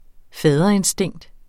Udtale [ ˈfæːðʌ- ]